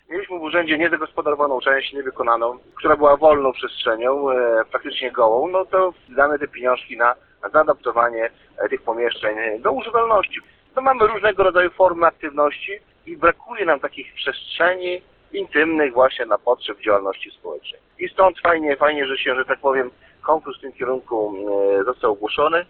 600 tysięcy złotych z tej puli trafi do gminy Wydminy. – Ta kwota pozwoli zaadaptować gminne pomieszczenie na Centrum Aktywności Lokalnej – mówi Radosław Król, wójt gminy.